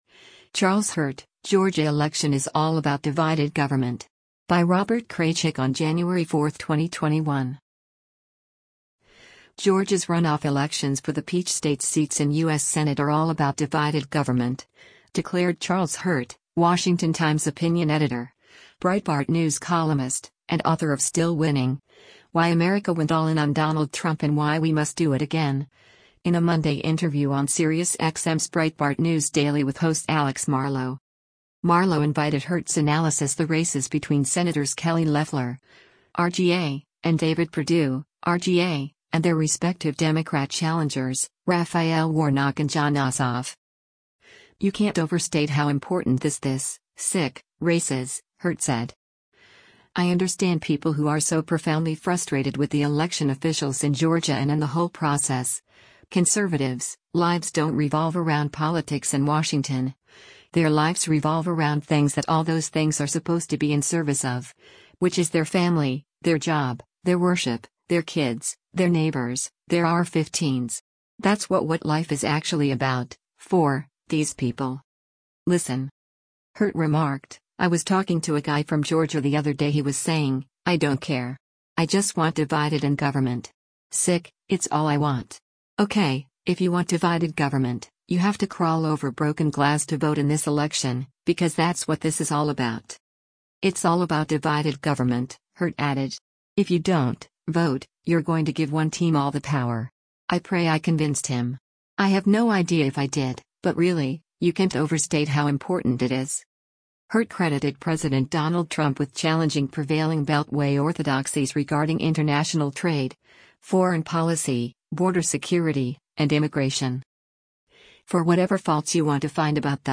interview
Breitbart News Daily broadcasts live on SiriusXM Patriot 125 weekdays from 6:00 a.m. to 9:00 a.m. Eastern.